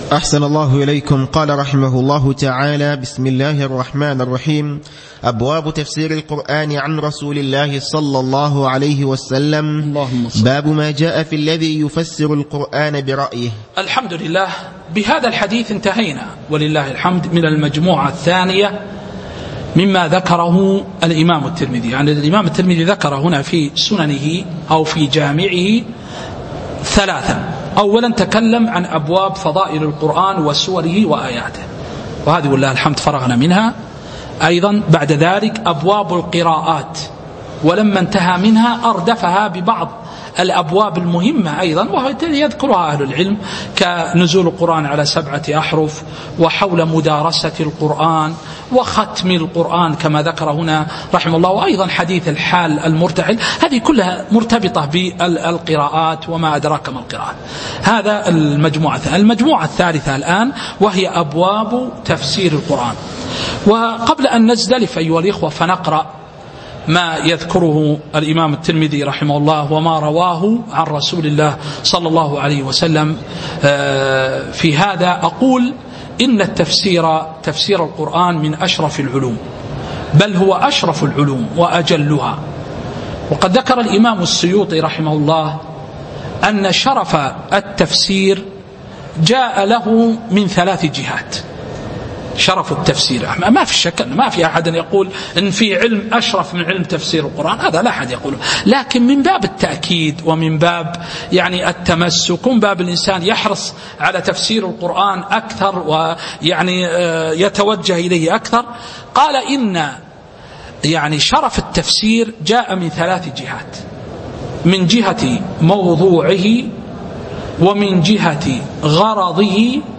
تاريخ النشر ٥ صفر ١٤٤٣ هـ المكان: المسجد النبوي الشيخ